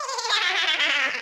Icon of an audio speaker. New Super Mario Bros. - A Boo's laugh
Boo_Laugh_2.oga